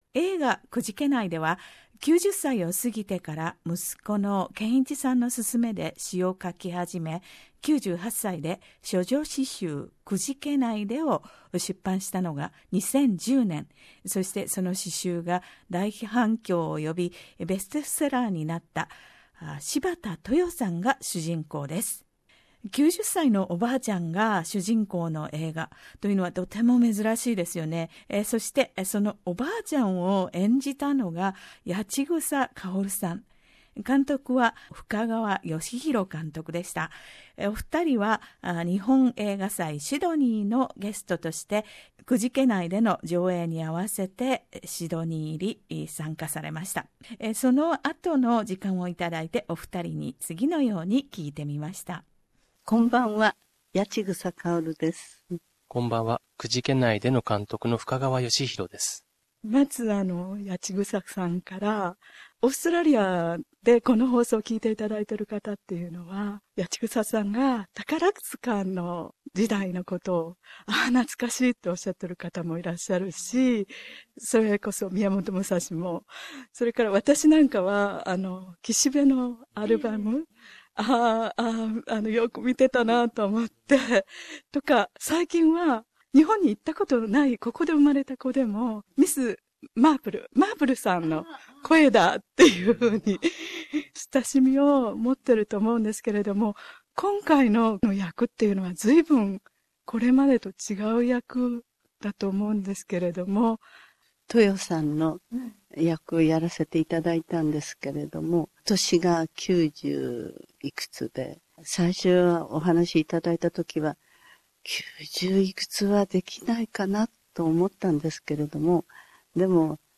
日本映画祭シドニーのゲスト、「くじけないで」主演の八千草薫さんと深川栄洋監督にインタビュー。柴田トヨさんの詩を八千草さんが朗読も。